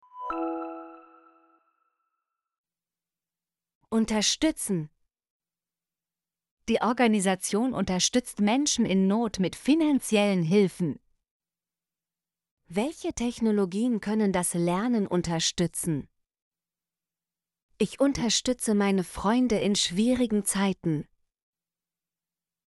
unterstützen - Example Sentences & Pronunciation, German Frequency List